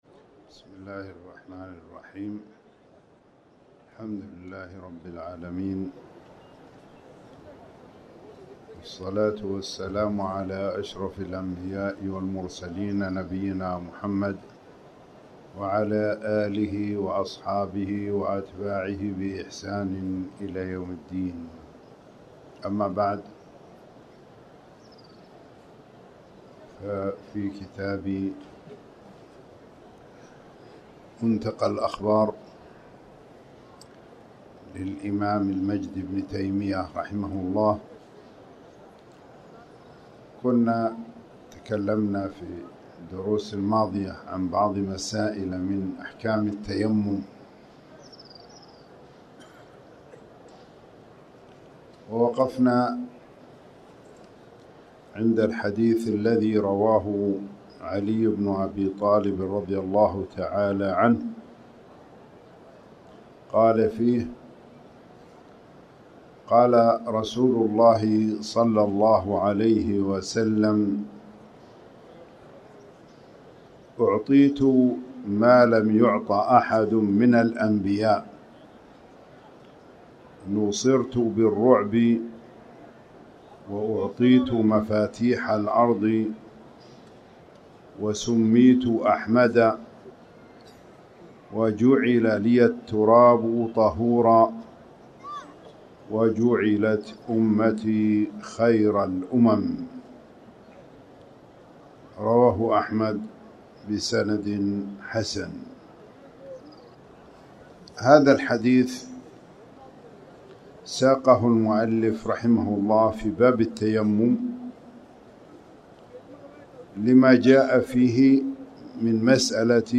تاريخ النشر ٣ رجب ١٤٣٩ هـ المكان: المسجد الحرام الشيخ